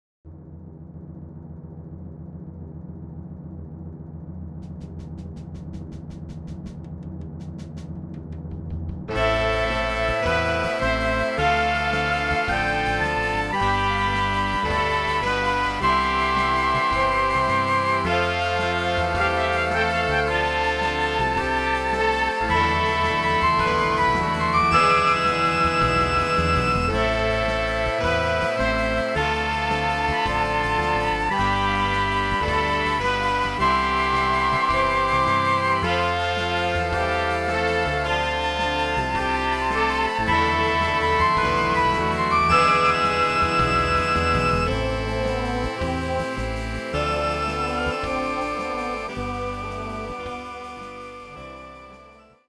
Solo z orkiestrą